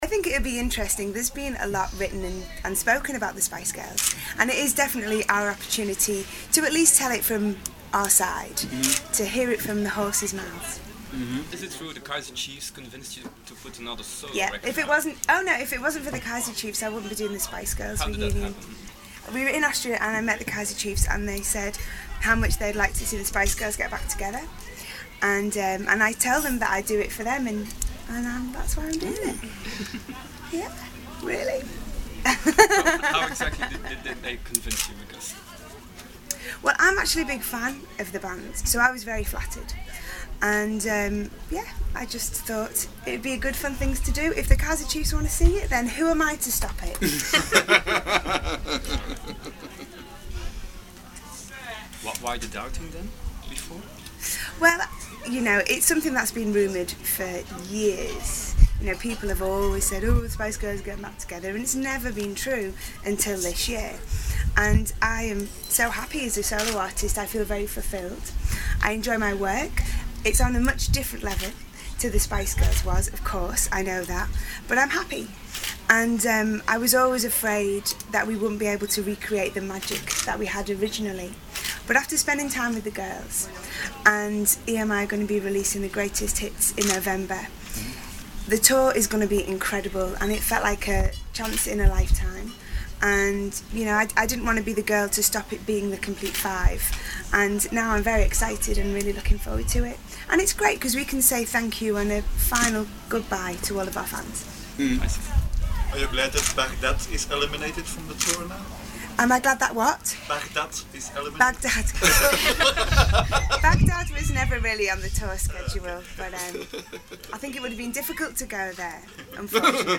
Melanie C did a press interview in Belgium this past weekend, in which she revealed that the Kaiser Chiefs convinced her to go along with the Spice Girls Reunion. She also confirmed that the Spice Girls' Greatest Hits album will be released in November.
Melanie C - Interview in Belgium (8.12.07).mp3